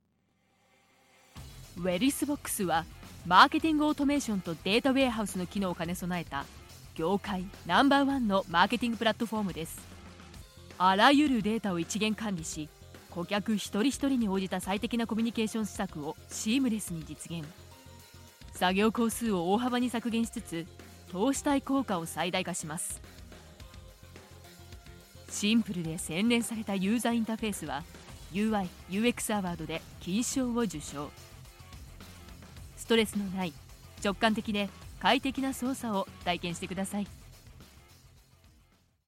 Female
TEENS, 20s, 30s
Sometimes, she is pure and friendly like a girl next door, at other times trustworthy, intellectual and authentic, on other occasions serious, and furthermore, can perform for cartoon characters.
YOGA -healing voice-.mp3
Microphone: zoom H4n pro